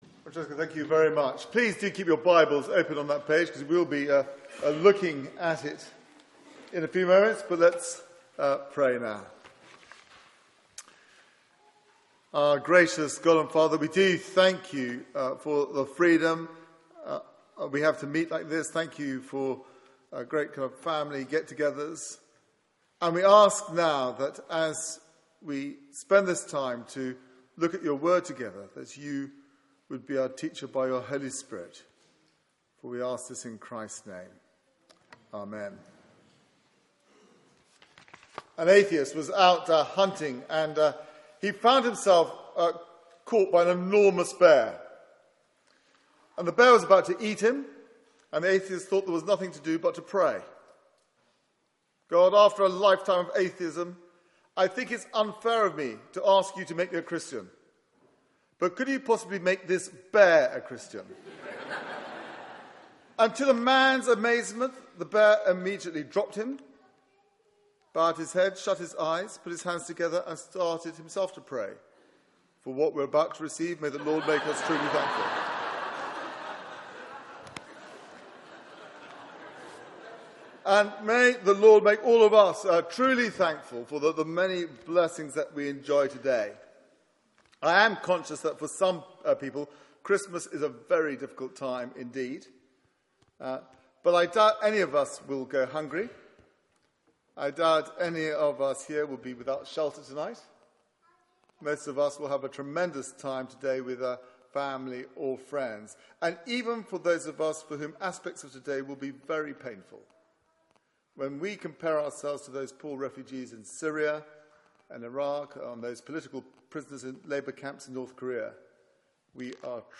Media for 6:30pm Service on Thu 25th Dec 2014 09:15 Speaker
Sermon